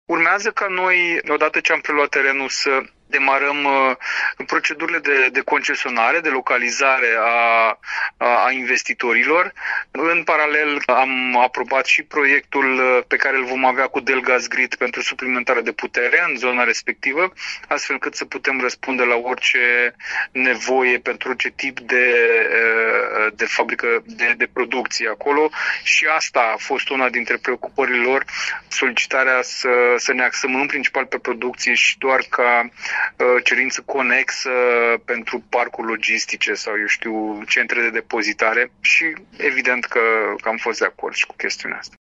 Vicepreședintele Consiliului Județean Iași, Marius Dangă, a explicat că sunt deja discuții cu reprezentanții Delgaz Grid privind extinderea capacităților din zonă, astfel încât să fie operaționale mai multe unități de producție: Urmează ca noi, odată ce am preluat terenul, să demarăm procedurile de concesionare, de localizare a investitorilor.